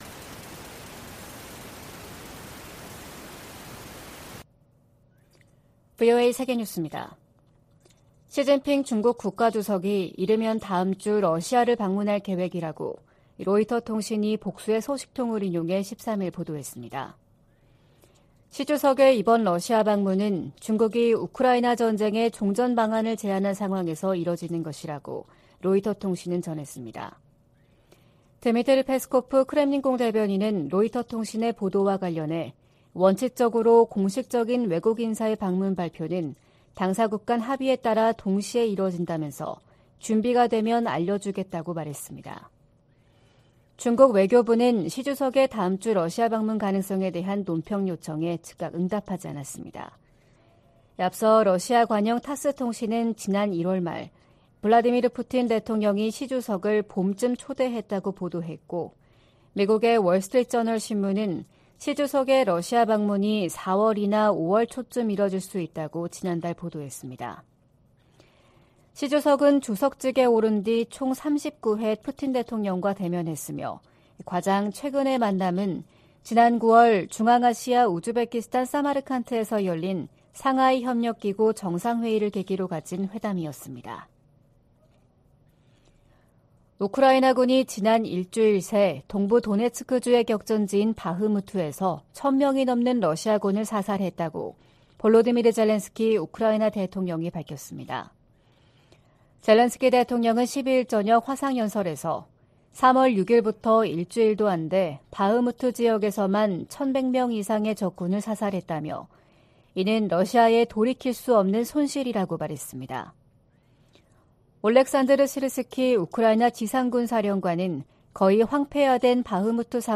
VOA 한국어 '출발 뉴스 쇼', 2023년 3월 14일 방송입니다. 북한이 12일 전략순항미사일 수중발사훈련을 실시했다고 다음날 대외 관영 매체들이 보도했습니다. 미국과 한국은 ‘자유의 방패’ 연합연습을 시작했습니다. 미 국무부가 북한의 최근 단거리 탄도미사일 발사를 규탄하며 대화 복귀를 촉구했습니다.